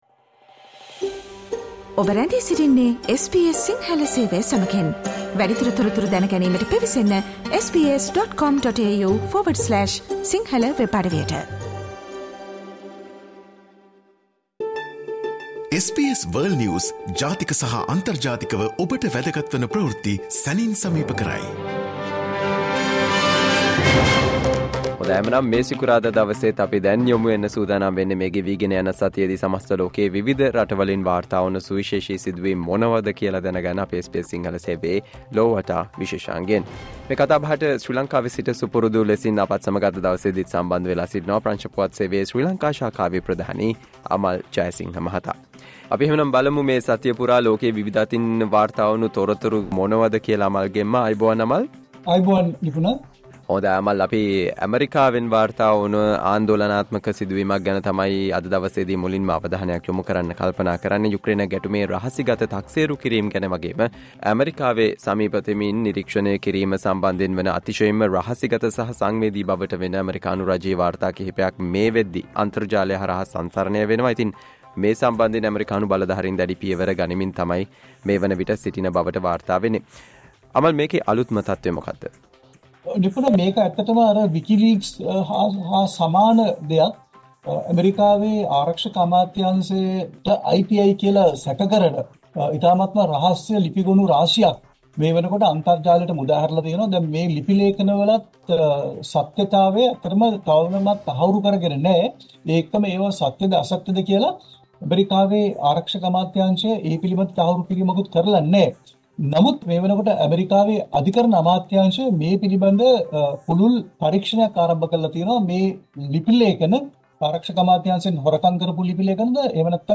World's prominent news highlights in 14 minutes - listen to the SBS Sinhala Radio weekly world News wrap every Friday Share